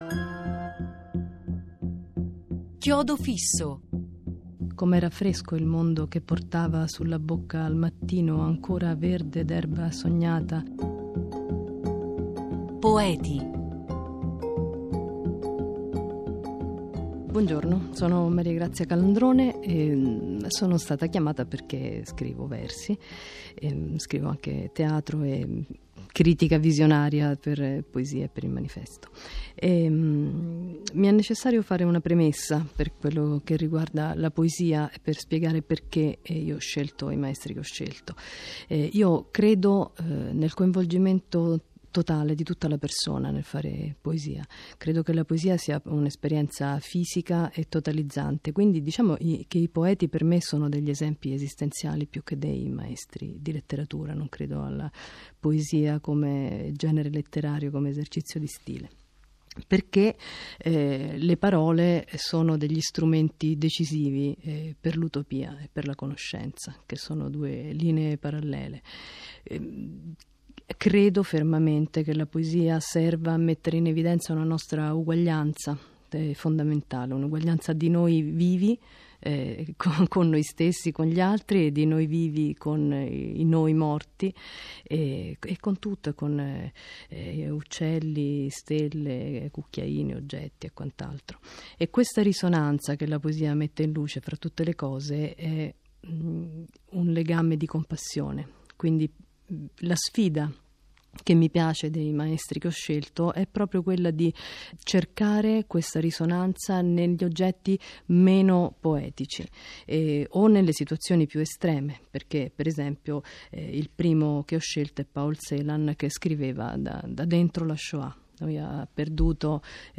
Brano musicale: Waldersgespräch, lied n. 3 op. 39 di Robert Schumann, eseguito da Dietrich Fischer Dieskau